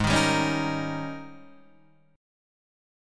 G# Major 7th Suspended 2nd Suspended 4th
G#M7sus2sus4 chord {4 6 5 6 4 6} chord